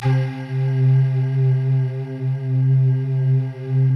Index of /90_sSampleCDs/Optical Media International - Sonic Images Library/SI1_Soft Voices/SI1_ShortSftVoic